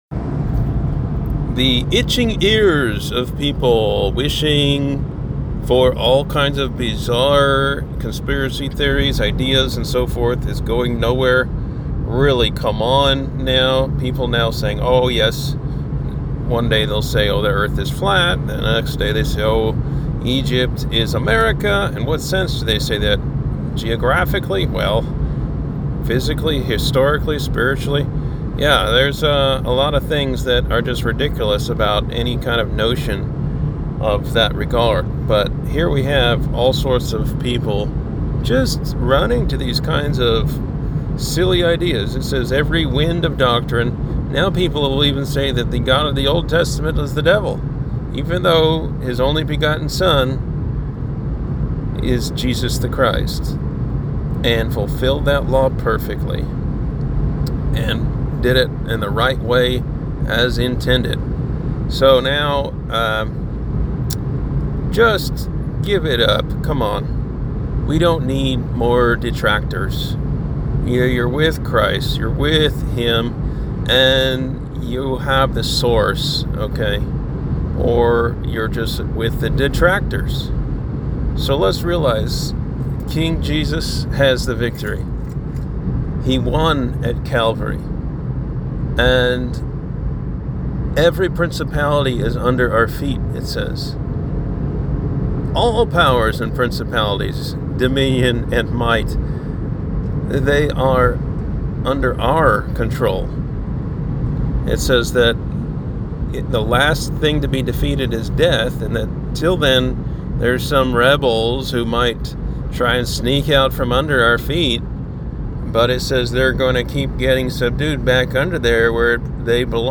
A quick message